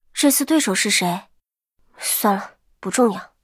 尘白禁区_安卡希雅辉夜语音_开始2.mp3